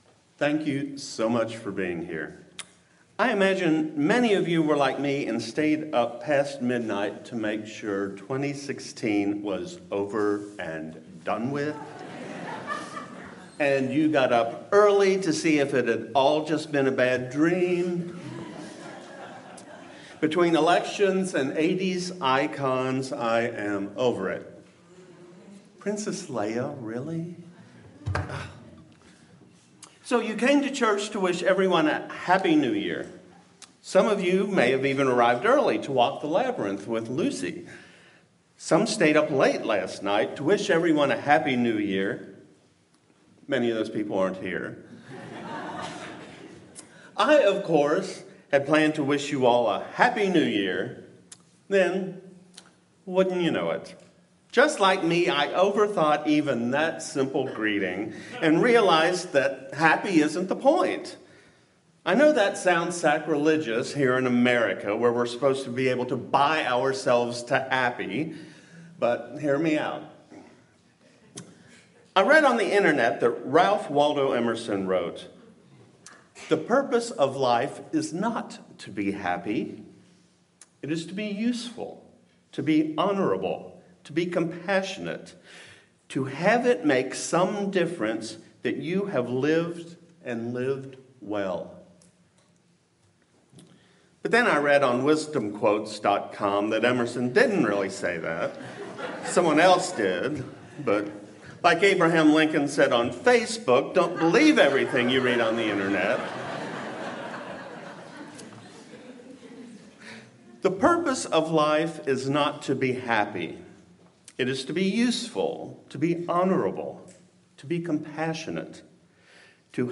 Sermon-Hereby-Resolved.mp3